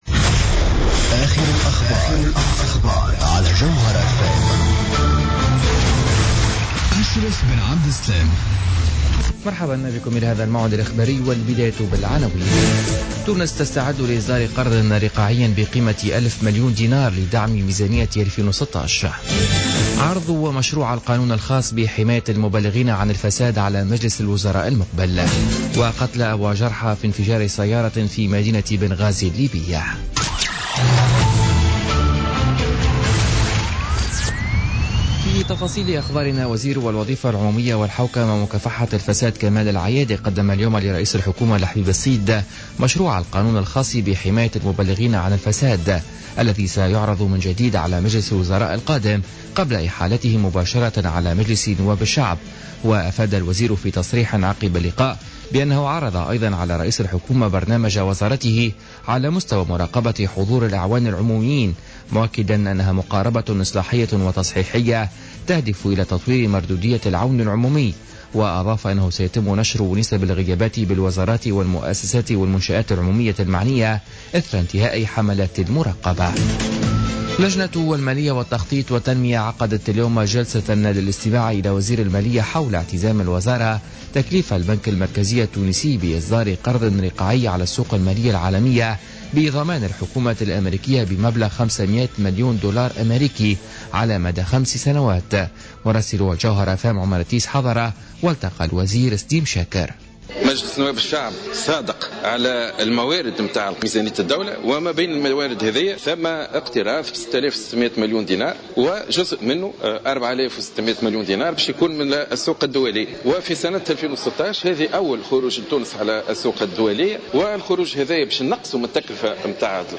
نشرة أخبار الخامسة مساء ليوم السبت 11 جوان 2016